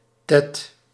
Ääntäminen
IPA: /brœst/